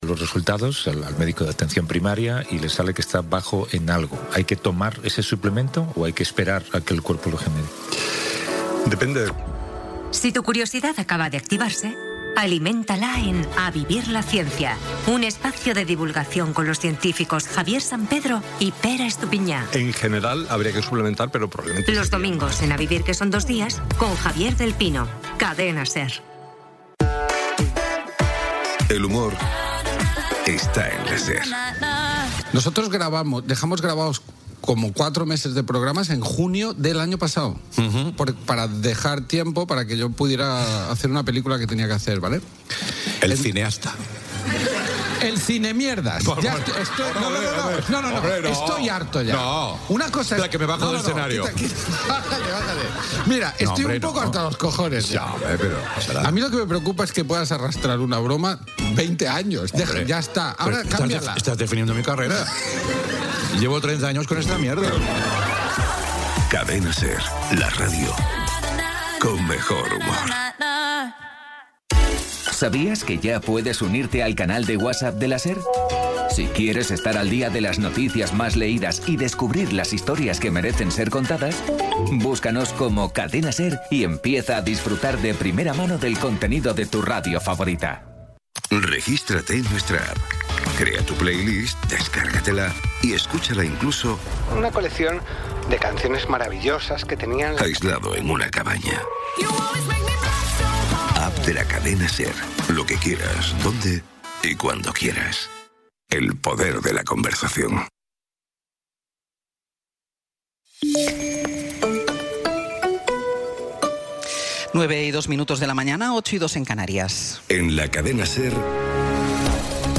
Resumen informativo con las noticias más destacadas del 14 de abril de 2026 a las nueve de la mañana.